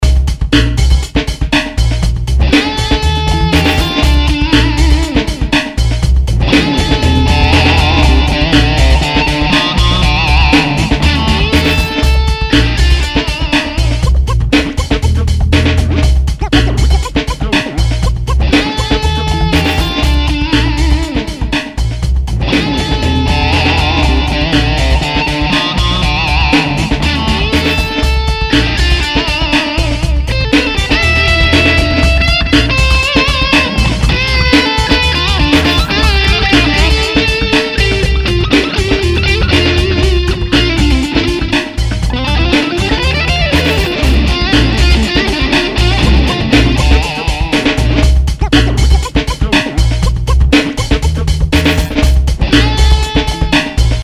Jingles to listen to:
synthétiseur, chant, guitare, percussion, drums